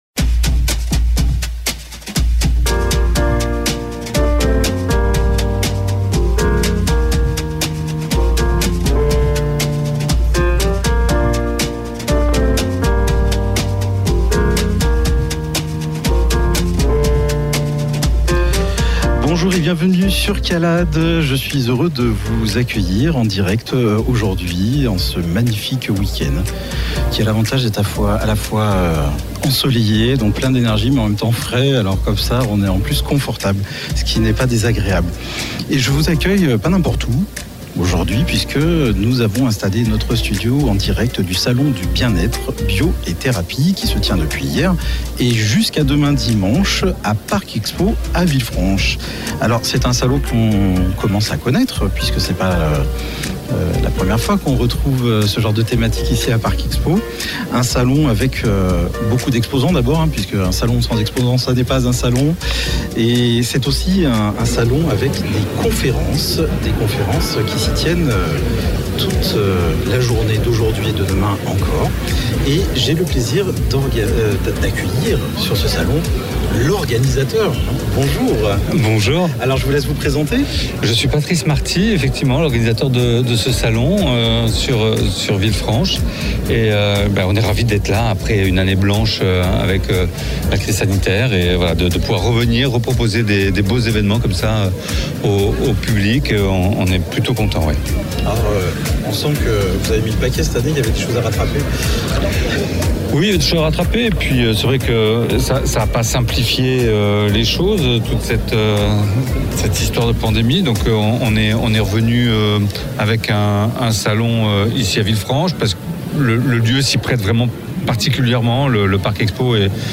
SALON-DU-BIEN-ETRE-DUPLEX-def_mixage-final-site.mp3